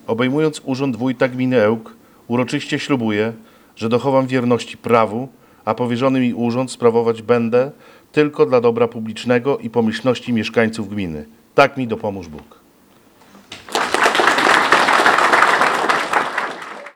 slubowanie-1.wav